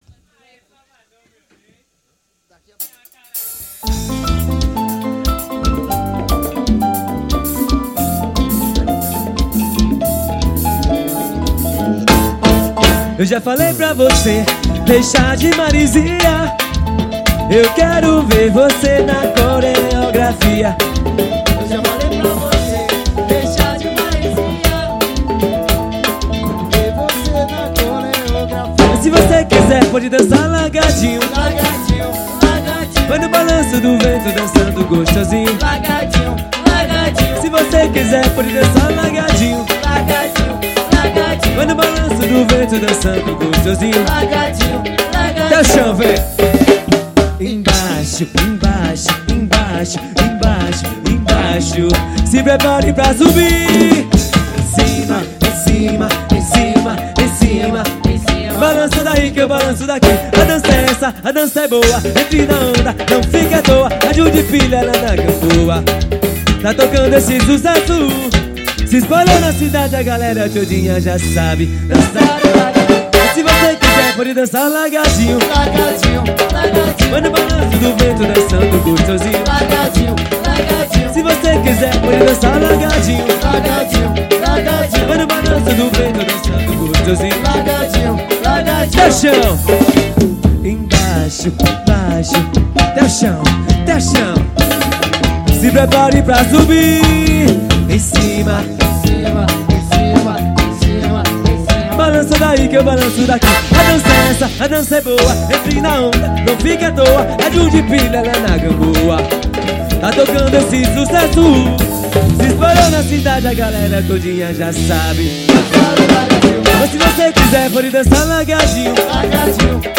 axe.